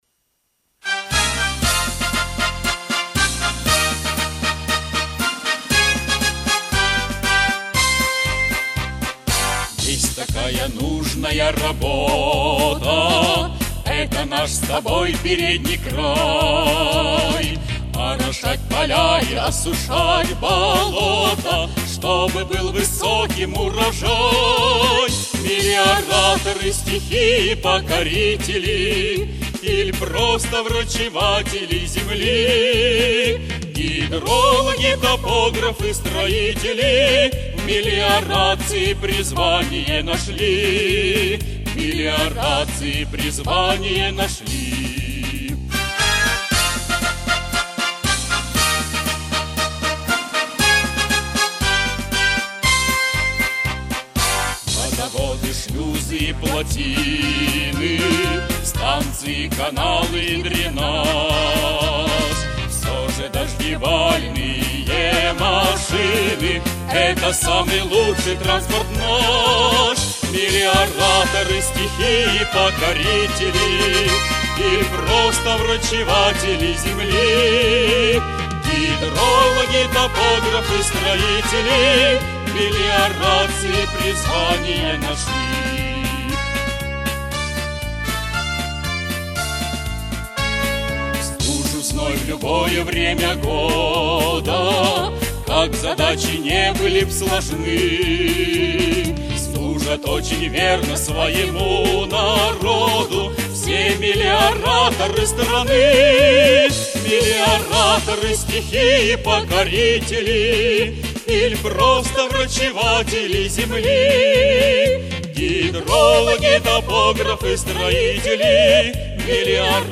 в исполнении автора музыки